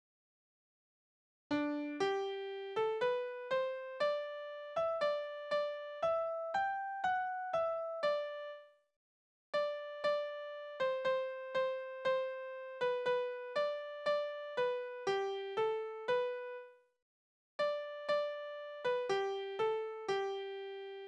Liebeslieder:
Tonart: G-Dur
Taktart: 4/4
Tonumfang: Oktave, Quarte
Besetzung: vokal